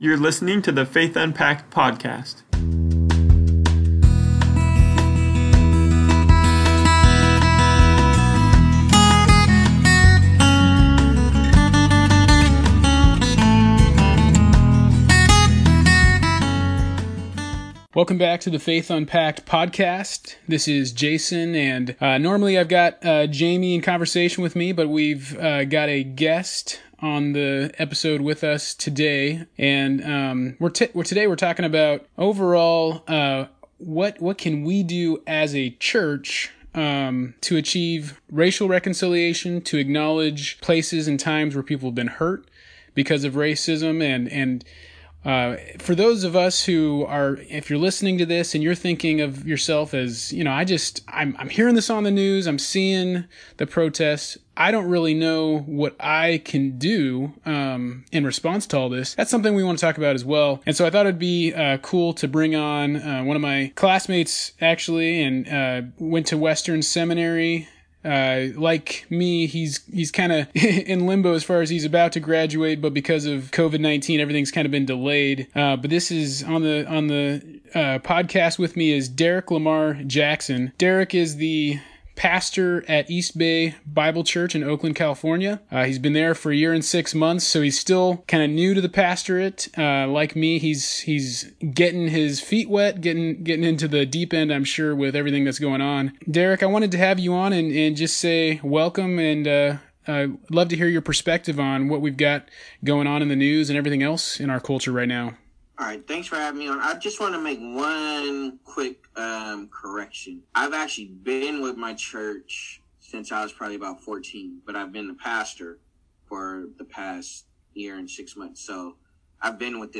Episode 62: The church and Race Reconciliation – An interview